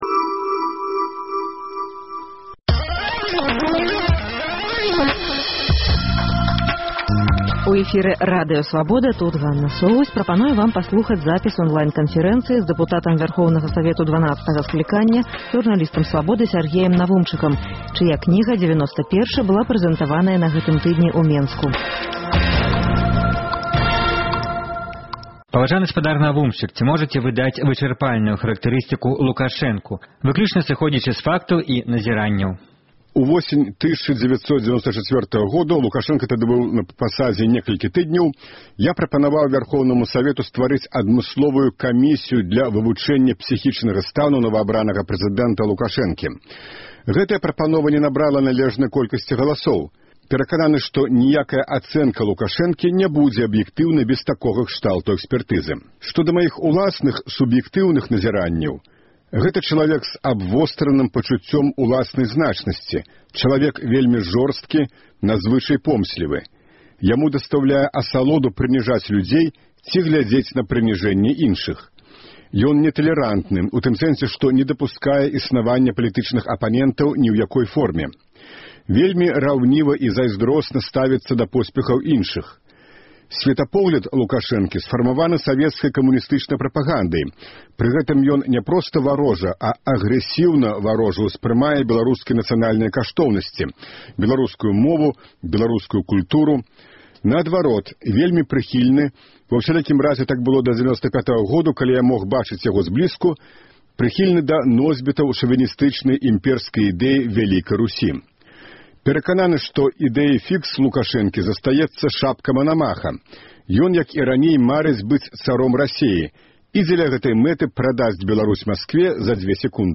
На вашы пытаньні падчас онлайн-канфэрэнцыі адказаў супрацоўнік беларускай службы Радыё Свабода, былы дэпутат Вярхоўнага Савету 12-га скліканьня, аўтар кнігі “Дзевяноста першы” Сяргей Навумчык.